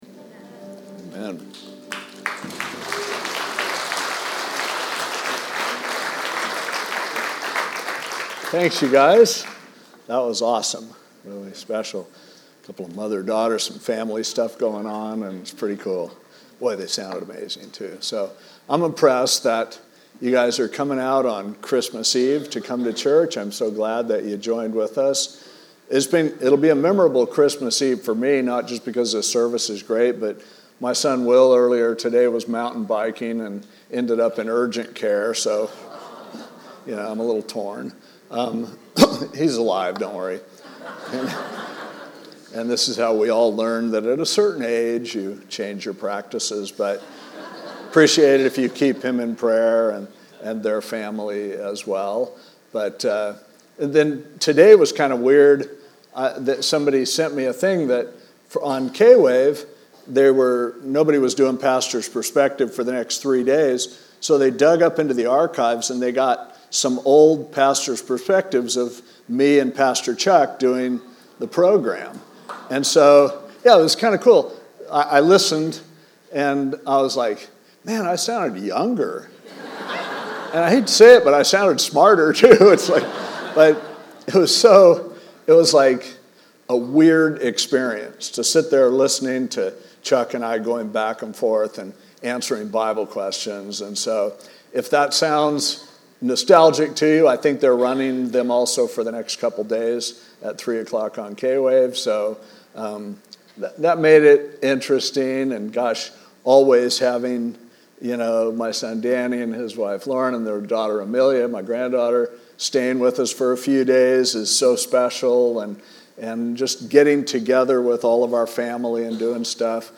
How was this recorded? DR-CHRISTMASEVE2024-JOHN1.mp3